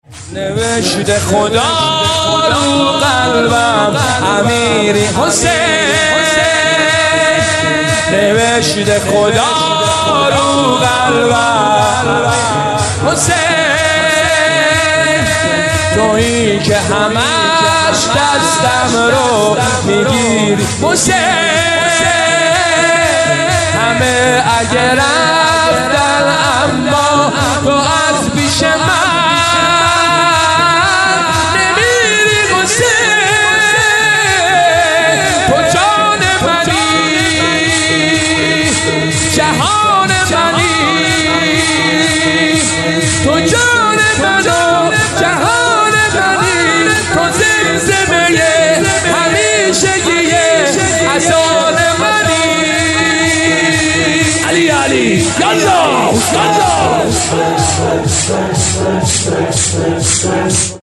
• نوحه و مداحی ~ ویژه
مراسم هر شب محرم ساعت 21
( شب سوم محرم )
شور